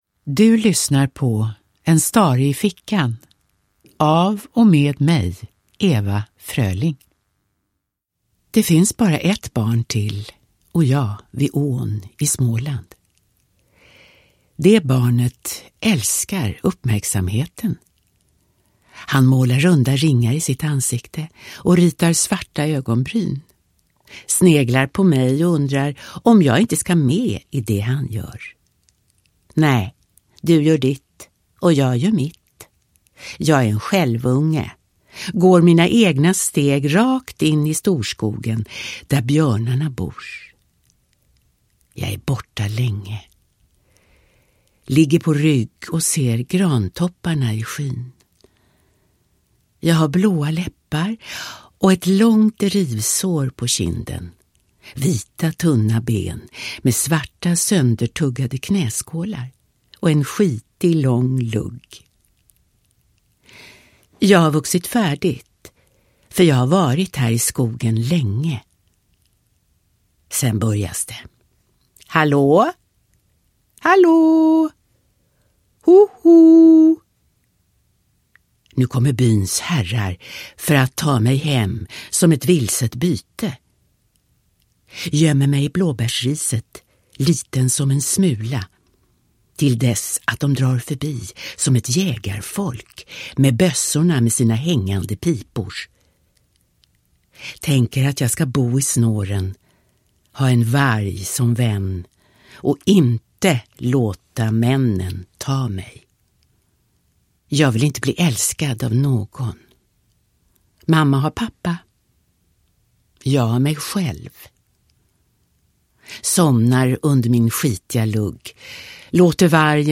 En stare i fickan – Ljudbok
Uppläsare: Ewa Fröling